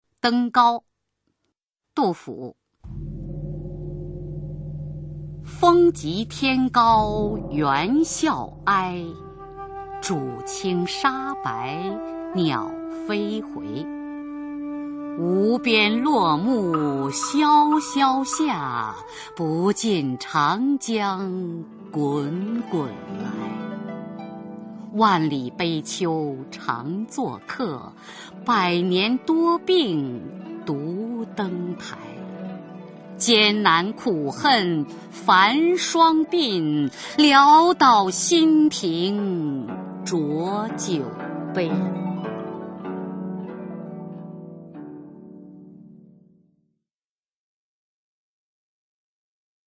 [隋唐诗词诵读]杜甫-登高（女） 唐诗朗诵